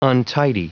Prononciation du mot untidy en anglais (fichier audio)
Prononciation du mot : untidy